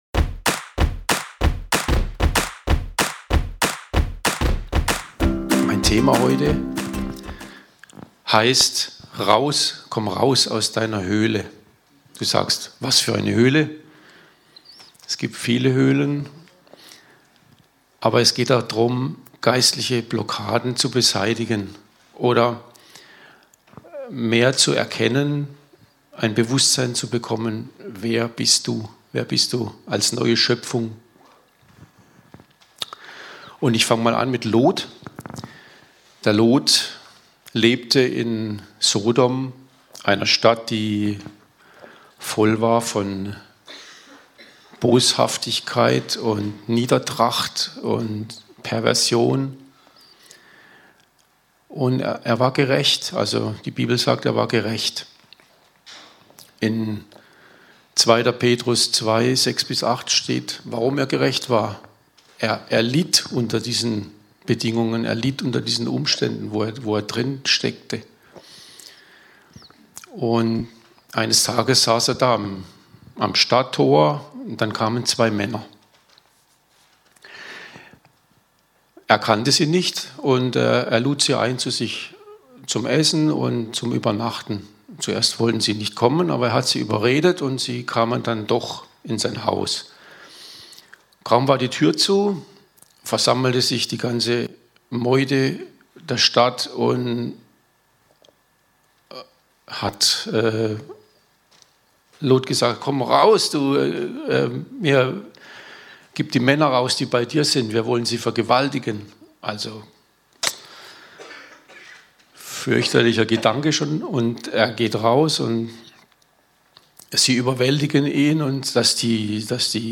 Wenn du dich selber als neue Schöpfung siehst, dann kannst du tun was du noch nie gemacht hast. Die allererste Predigt